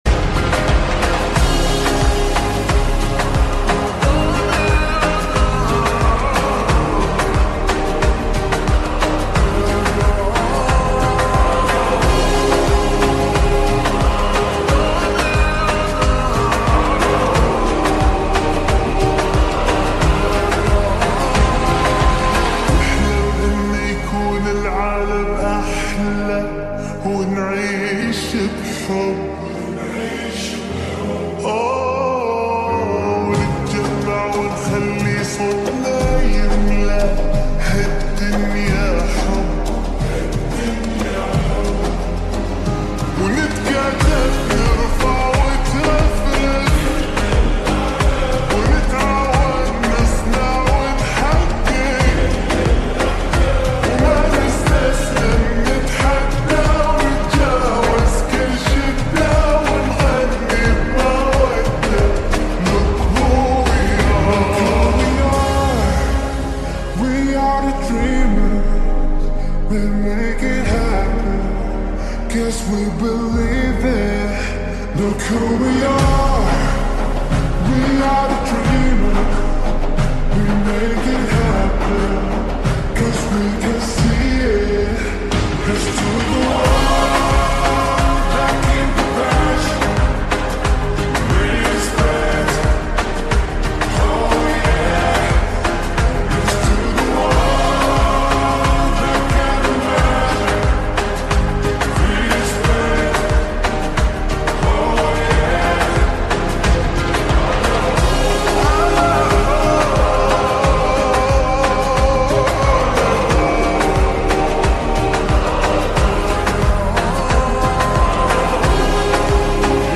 Engine Oil And Filter Change Sound Effects Free Download